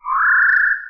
8 bits Elements
Fantasy Creatures Demo
bird_2.wav